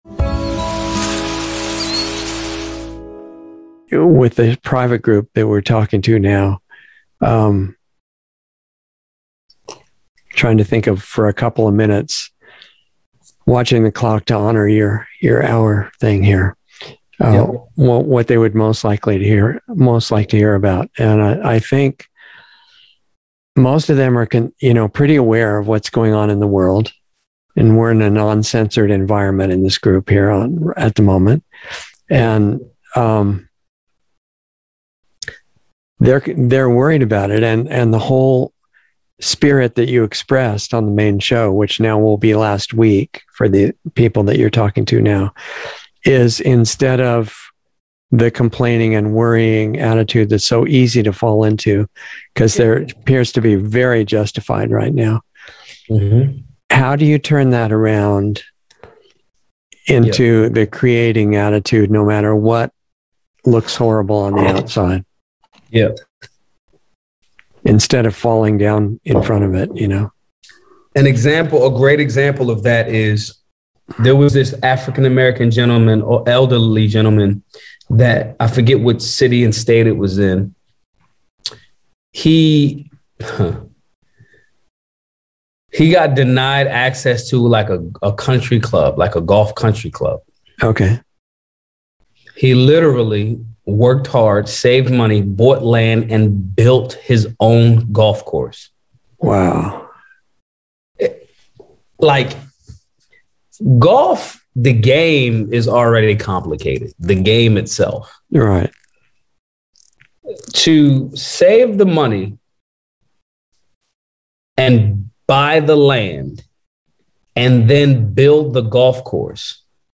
Insider Interview 11/24/21